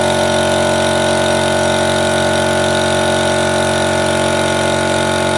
来自创客空间的声音 " 空气压缩机
描述：用变焦h4n记录的空气压缩机的短样本。除了标准化修整外，重新编码是未处理的
Tag: 结构 机械 机械 工业 字段 机器 空气 工作 工具 压缩机 噪声 记录 makerspace